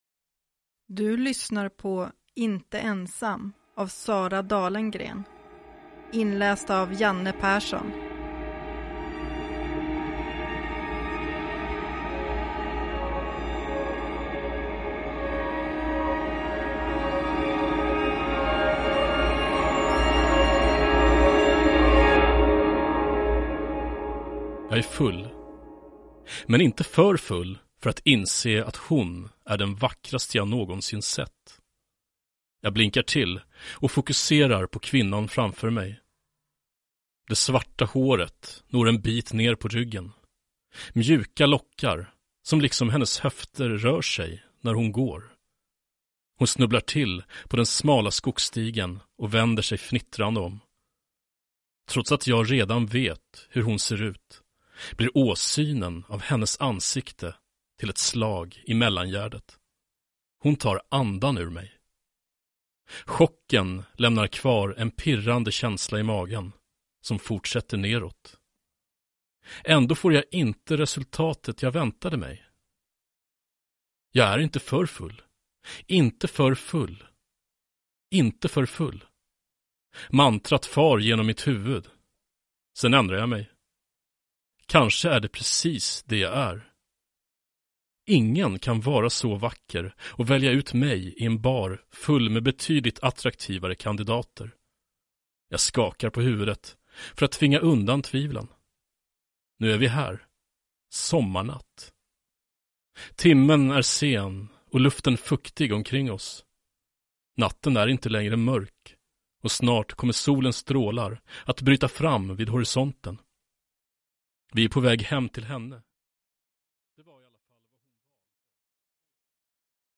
Inte ensam (ljudbok) av Sara Dalengren | Bokon